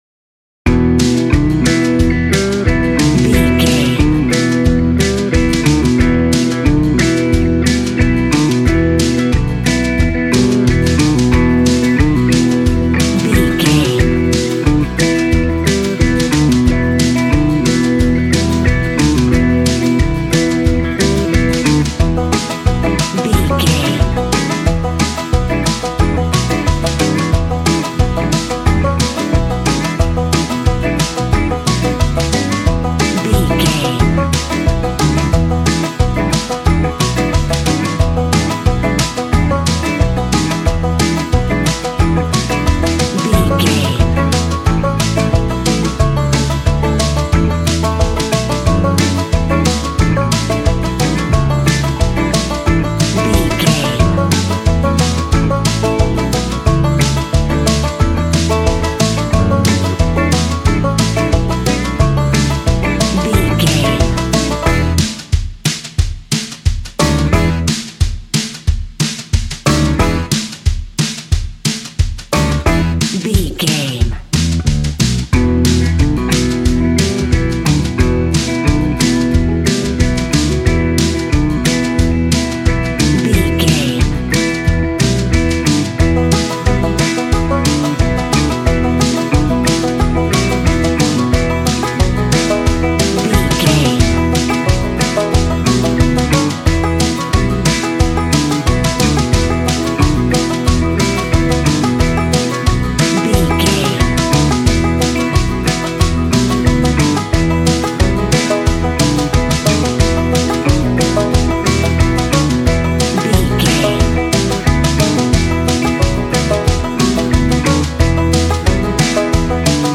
Classic country music for a rodeo cowboy show.
Ionian/Major
Fast
fun
bouncy
double bass
drums
acoustic guitar